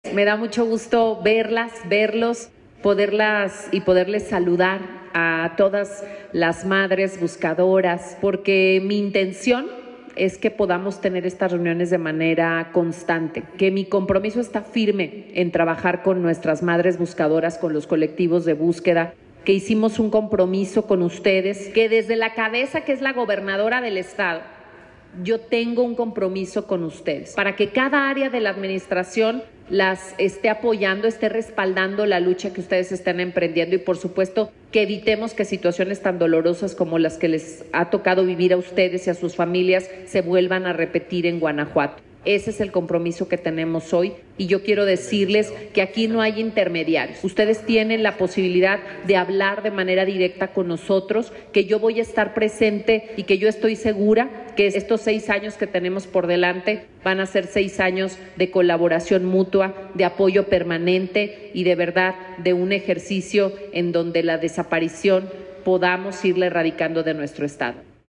Durante un diálogo abierto, las buscadoras independientes y representantes de colectivos de varios municipios del Estado, externaron inquietudes, dudas y peticiones a la Gobernadora y a las y los titulares de las dependencias estatales.
15-Octubre-Mensaje-de-Libia-Dennise-Dialogo-con-Buscadoras-Gto-.mp3